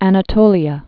(ănə-tōlē-ə, -tōlyə)